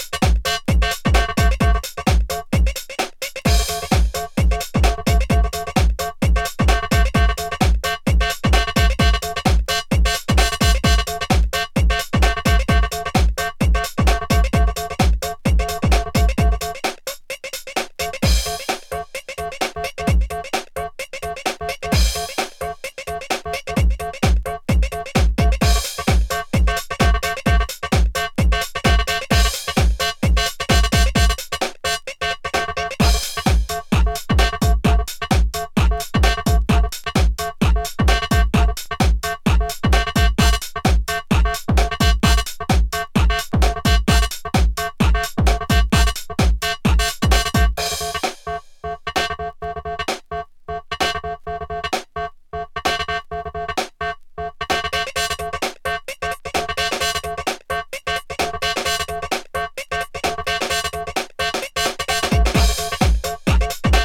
どこかカラッとした印象のサウンド。